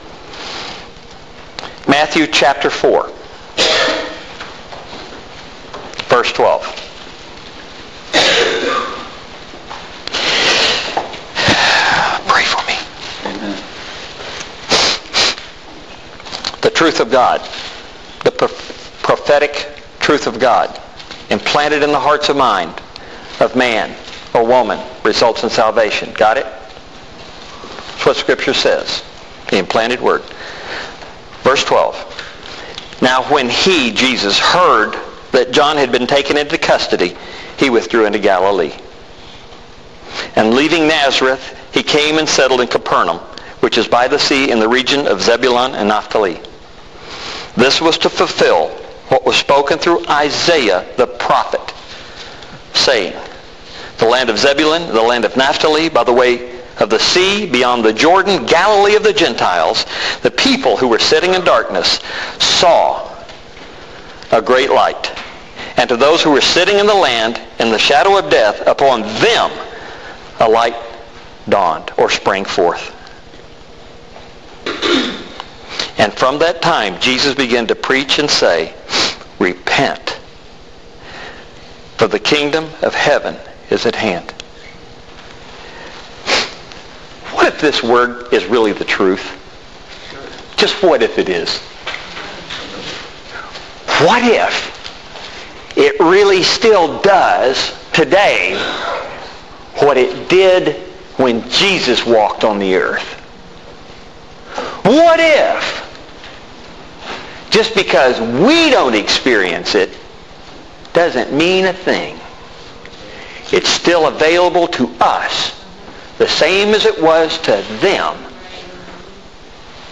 Resume Sermon Examples